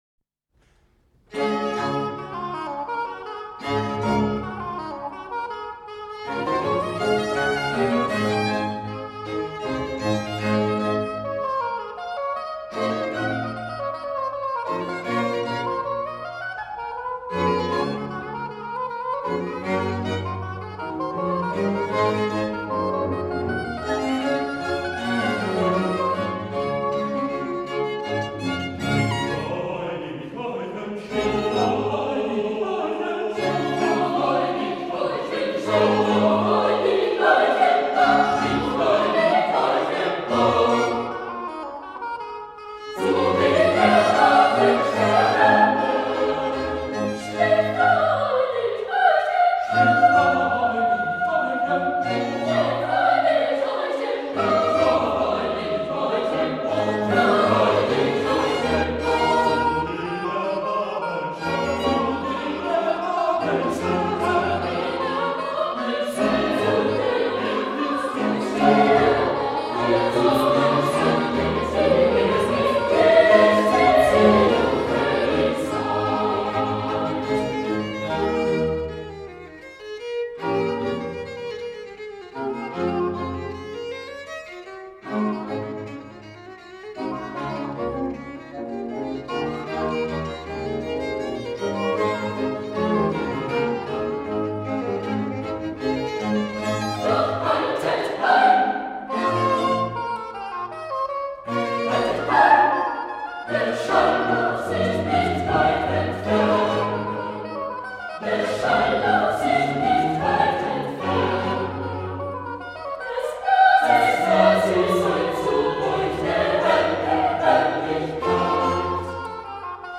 Posted in Musica Sacra | Tagged | 1 Comment